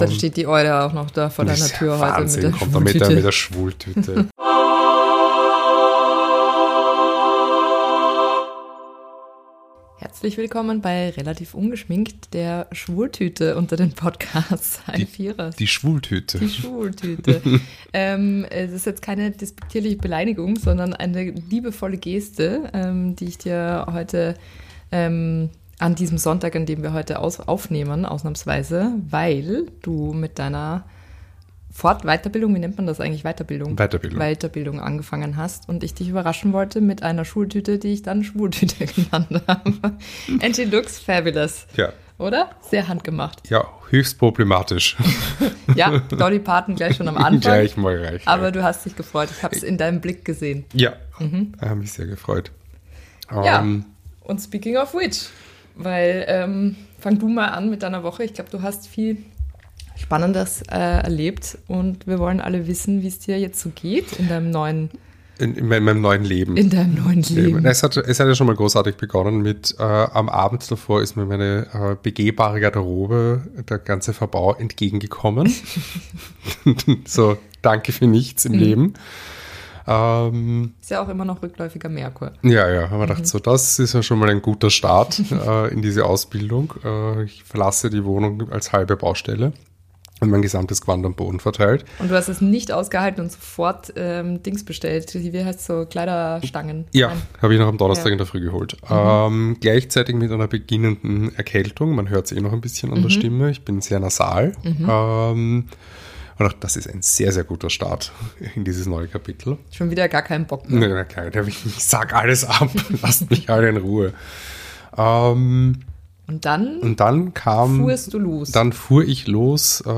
Eine Hälfte der Relativ Ungeschminkt Redaktion bildet sich weiter, die andere Hälfte bastelt Tüten. Ein Wochenrückblick am Sonntagstisch.